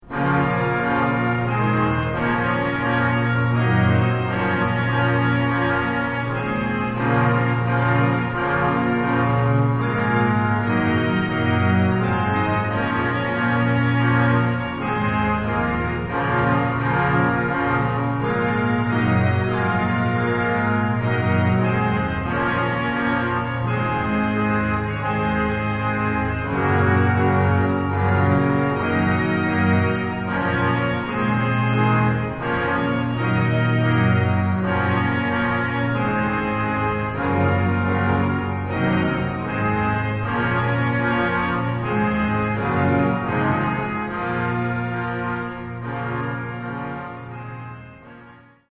eseguite all'organo a canne
Organo costruito dai Fratelli Collino nel 1887 a Torino